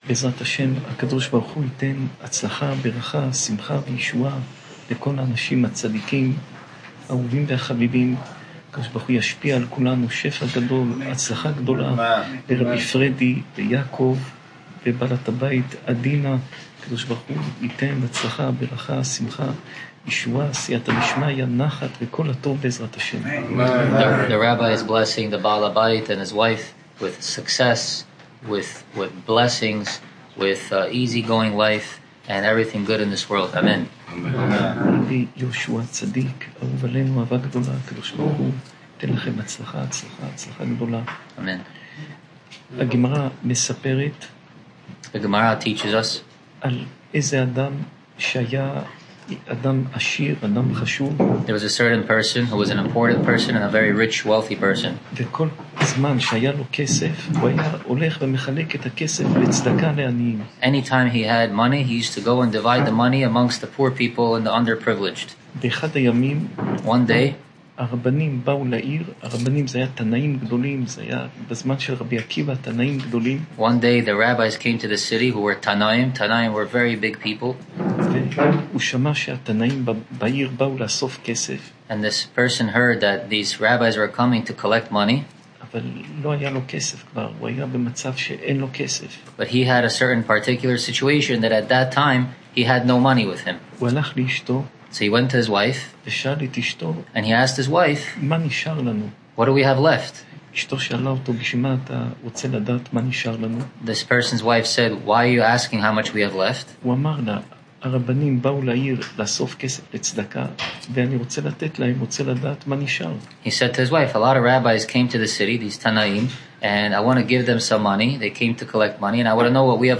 שעור תורה מפי הרב פינטו